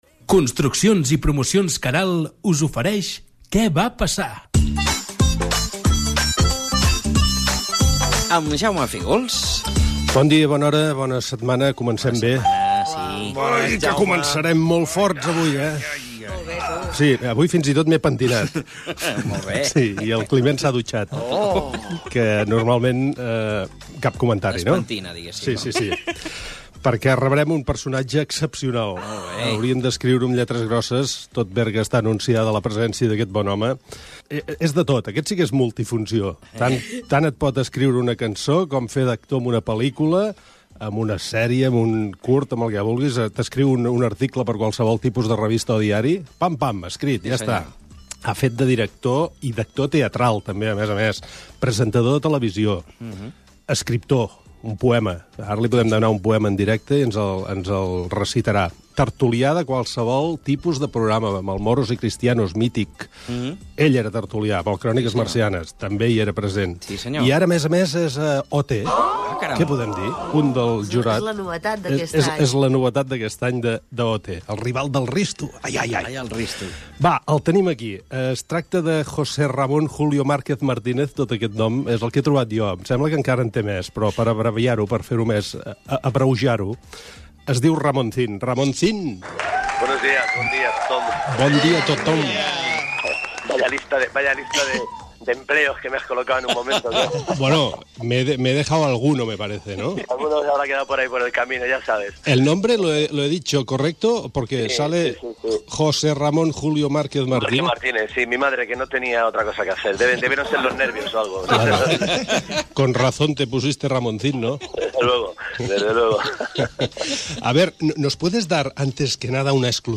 Espai "Què va passar?". Careta del programa amb publicitat i entrevista a Ramoncín (José Ramón Julio Martínez Márquez)
Entreteniment